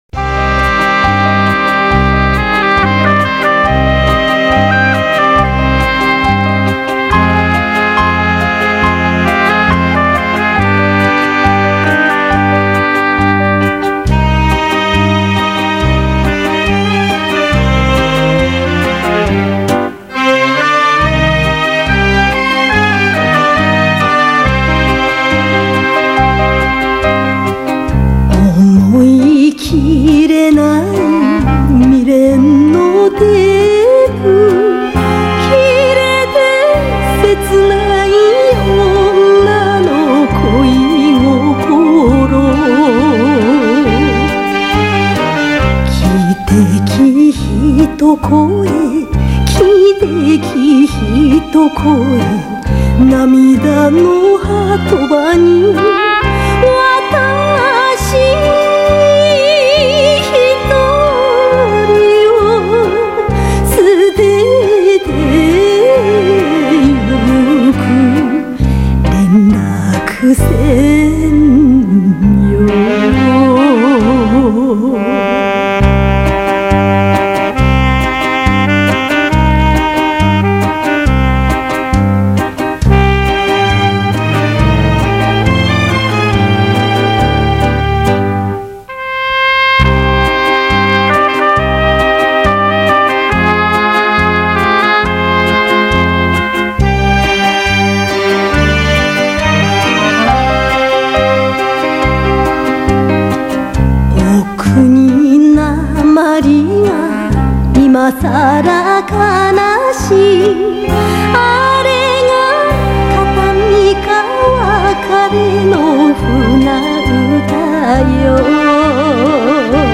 演歌敎材函